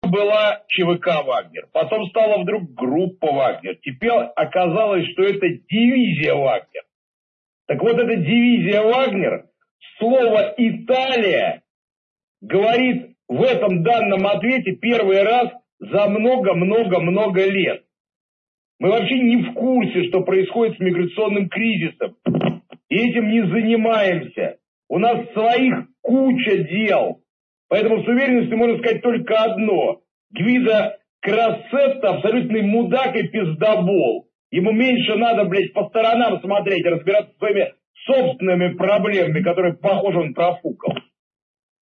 La risposta audio di Prigozhin (con traduzione).
La replica di Prigozhin (audio)